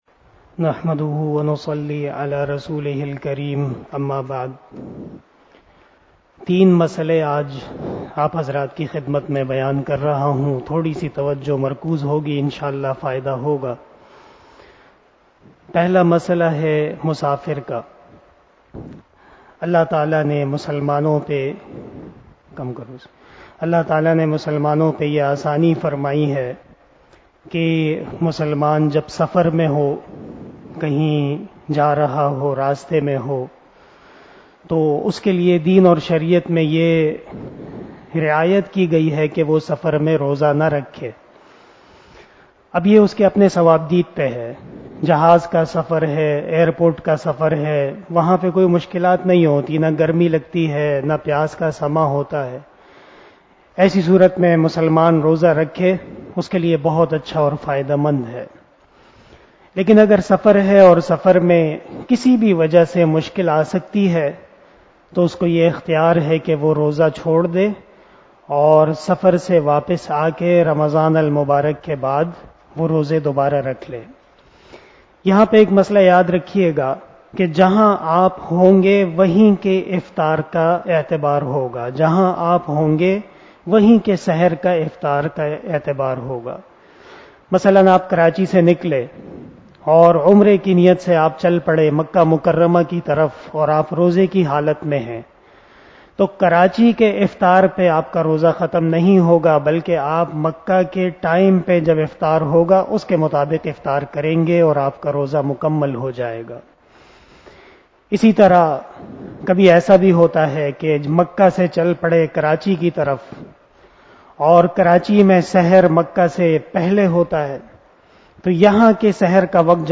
029 After Traveeh Namaz Bayan 05 April 2022 ( 04 Ramadan 1443HJ) Tuesday